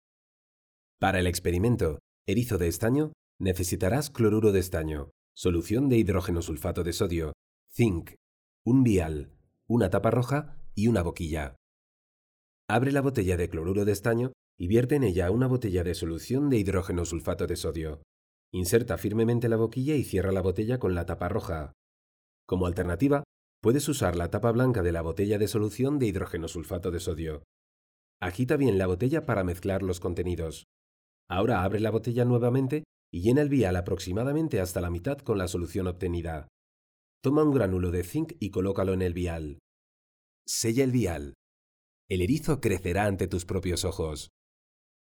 Spanish voice over artist. Will do any voice over in neutral Spanish
kastilisch
Sprechprobe: eLearning (Muttersprache):
Clear middle aged voice for any audiovisual project.